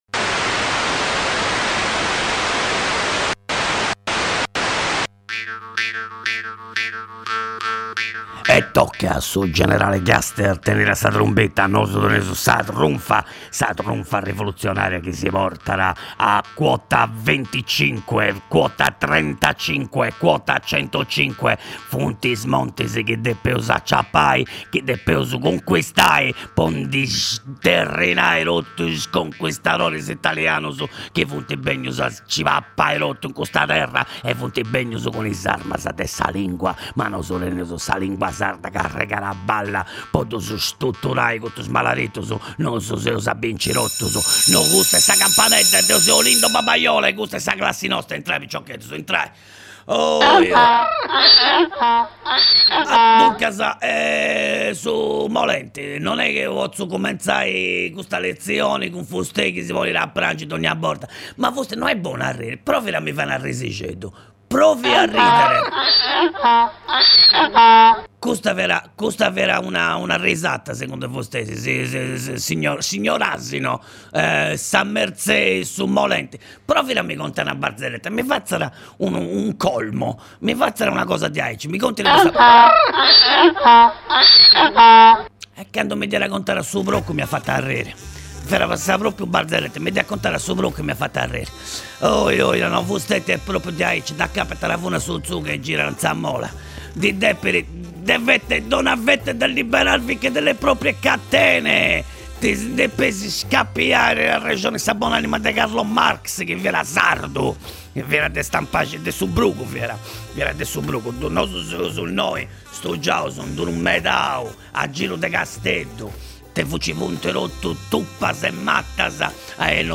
Professor Olindo Babaiola dogna di’ ìntrat a fura in is undas de Radio X po fai scola de sardu.